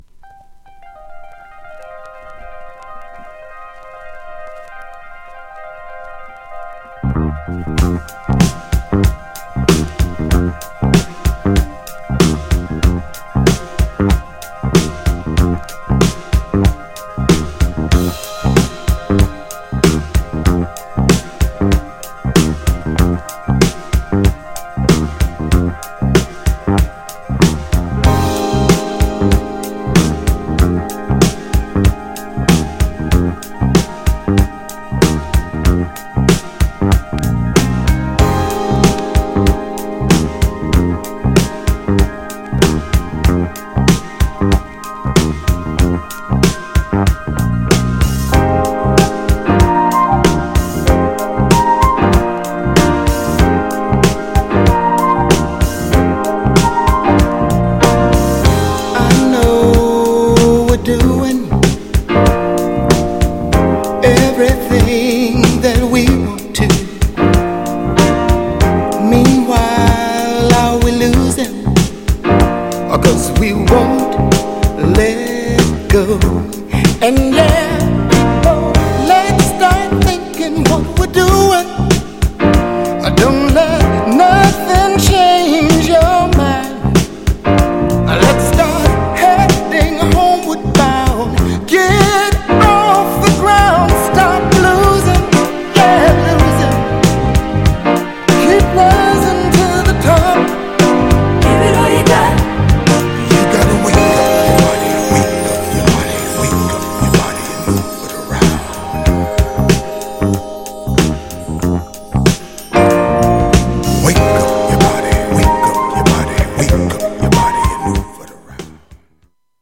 オリジナルの良さを残した好アレンジ!!
GENRE Dance Classic
BPM 91〜95BPM